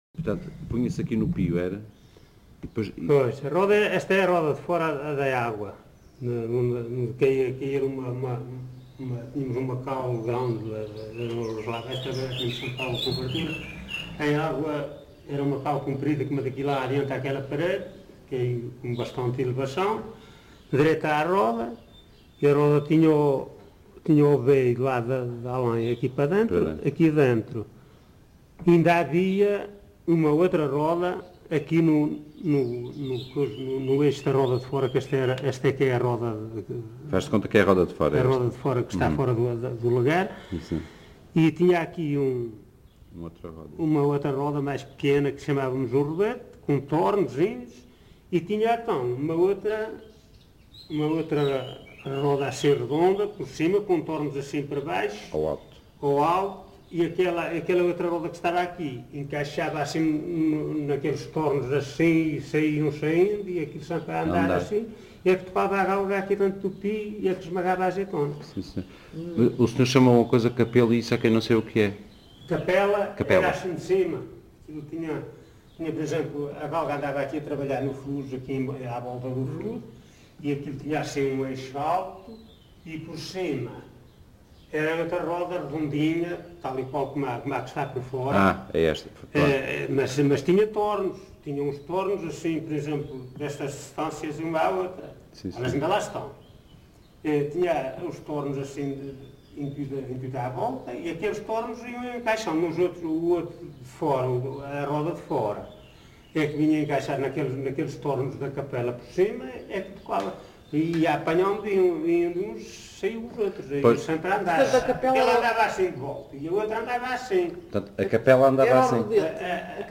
LocalidadePorto de Vacas (Pampilhosa da Serra, Coimbra)